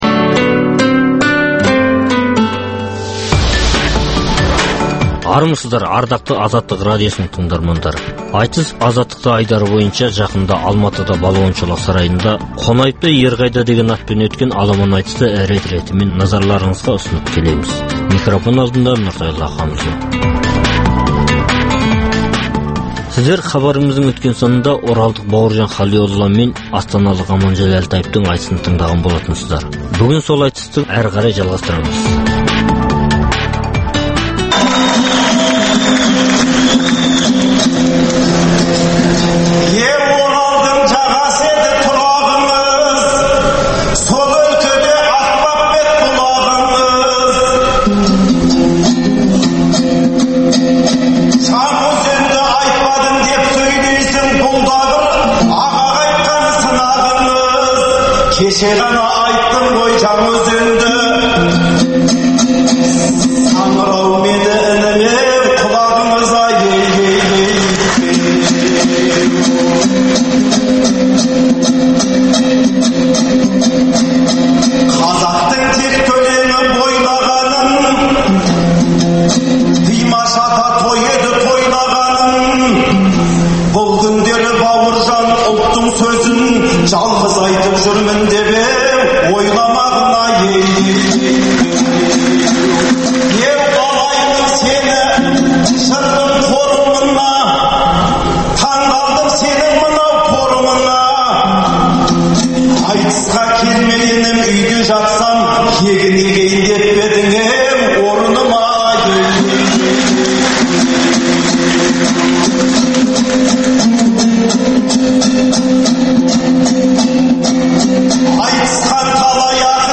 Айтыс - Азаттықта